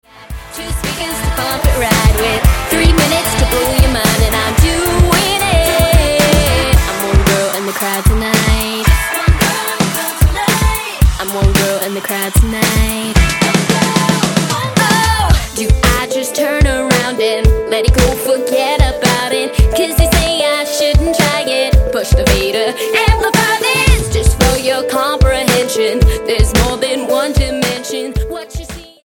Pop Album
Style: Rock